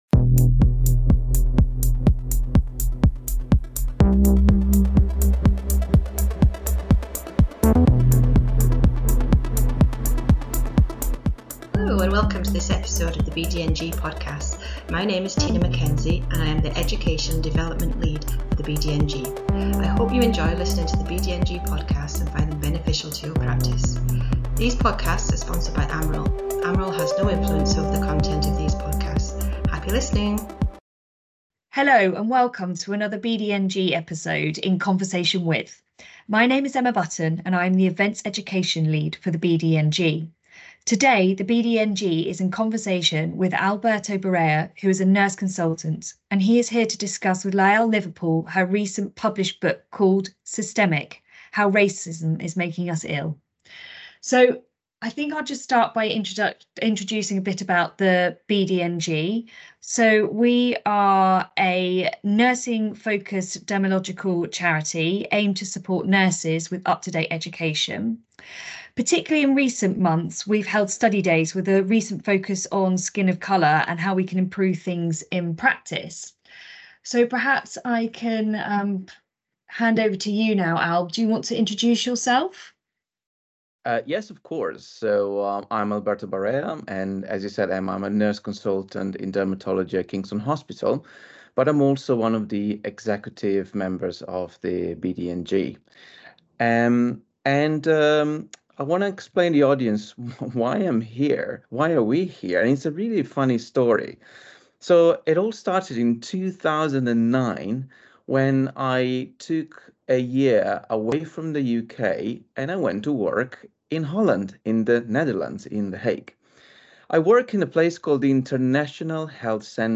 In conversation with …